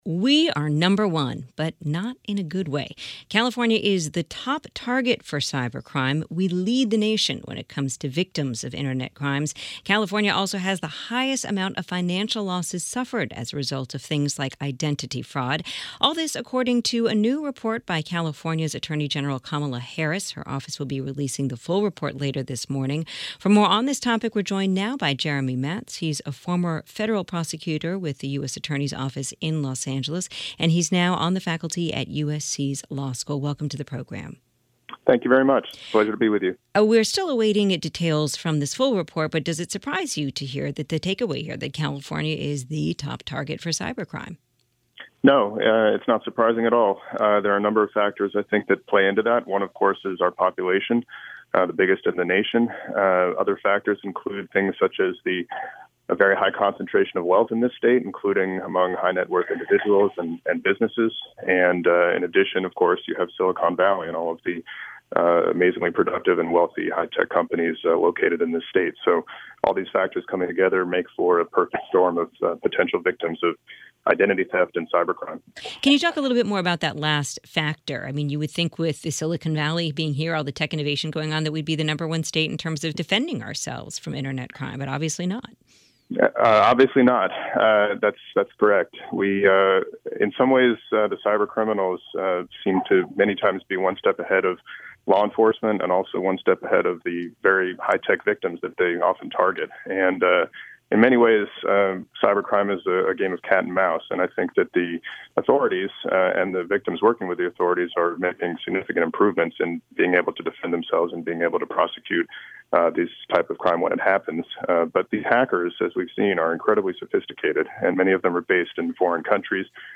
a guest speaker on KPCC-FM’s morning Articles show “Take Two